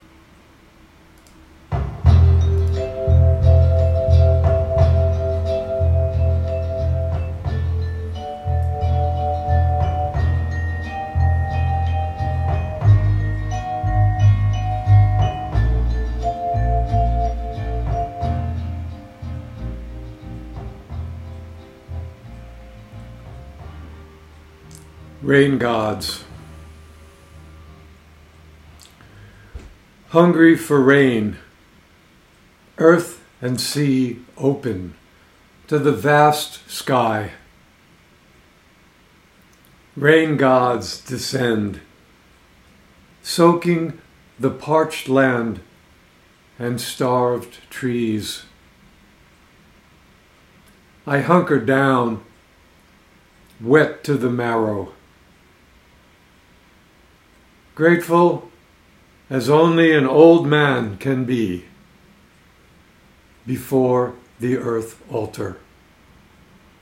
Reading of “Rain Gods” with music by The Beach Boys.